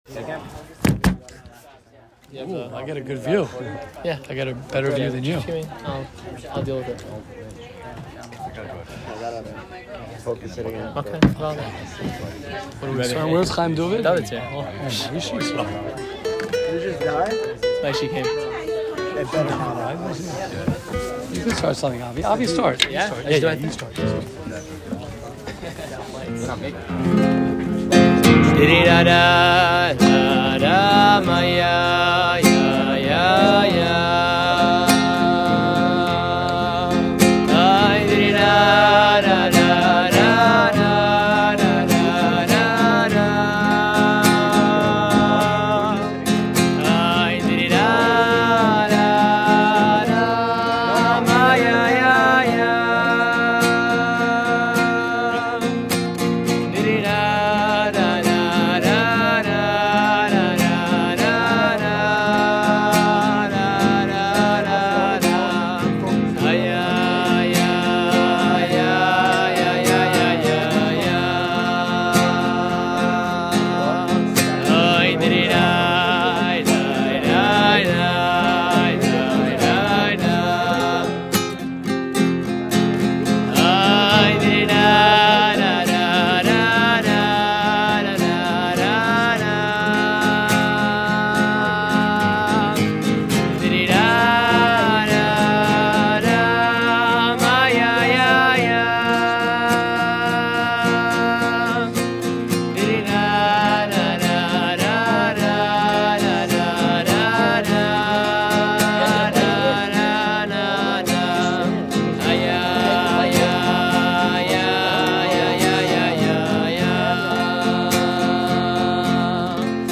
Farbrengen & Shiur in West Park @ The Ratner Home